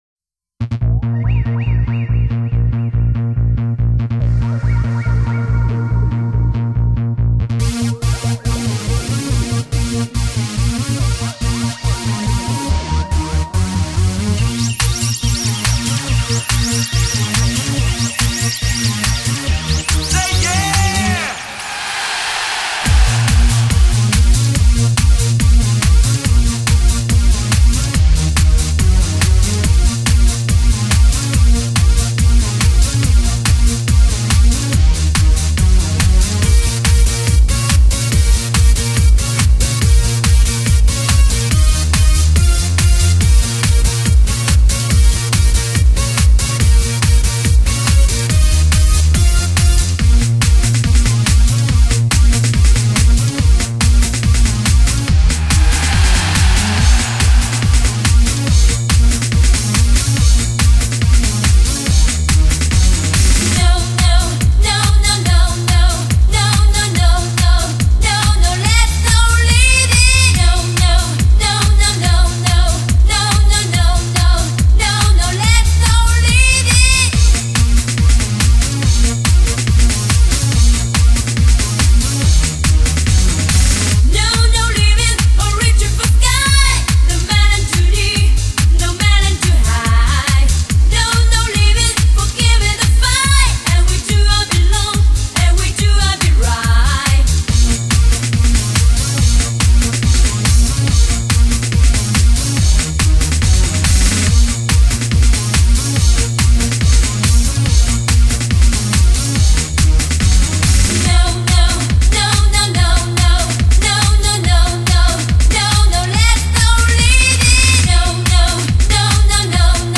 所属分类：音乐:发烧/试音:舞曲